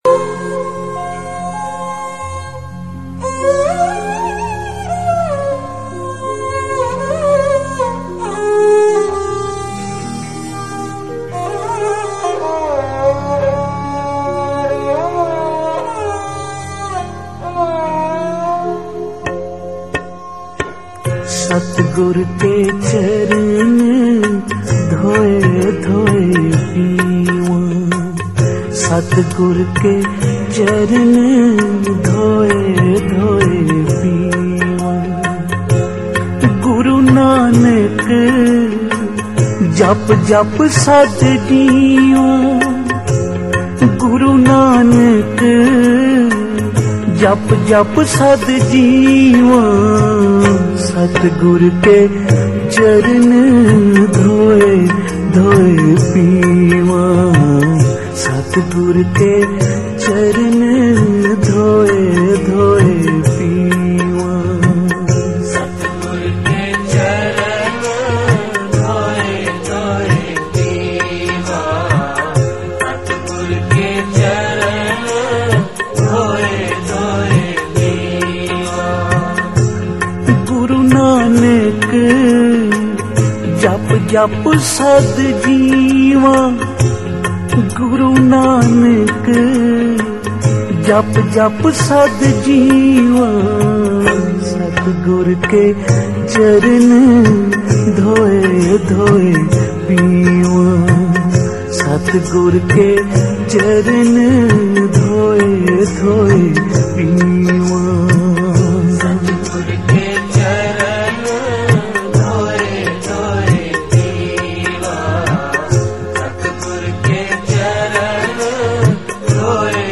Genre: Gurmat Vichar